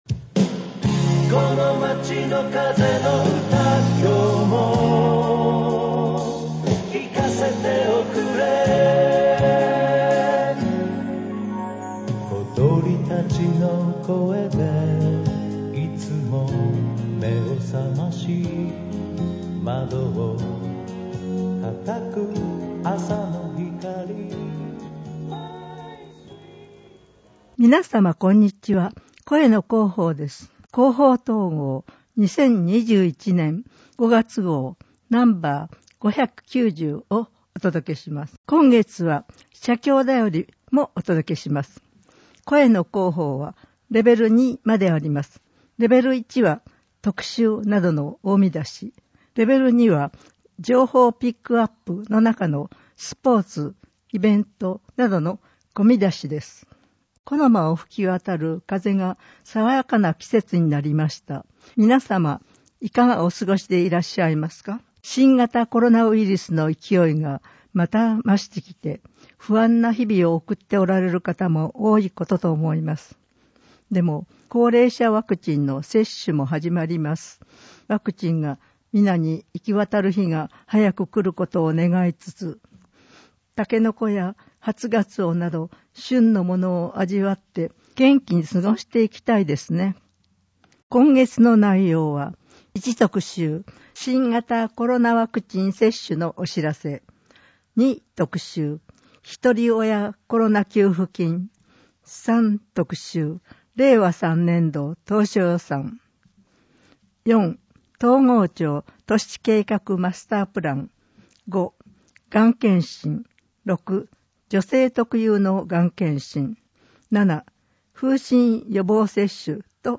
広報とうごう音訳版（2021年5月号）